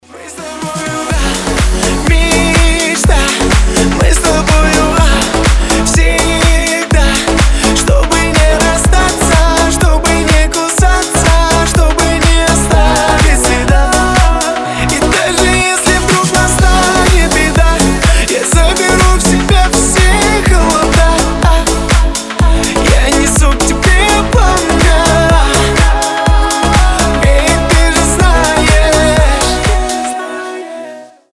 • Качество: 320, Stereo
громкие
Club House
ремиксы